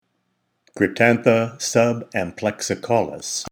Pronunciation/Pronunciación:
Cryp-tán-tha sub-am-plex-i-caú-lis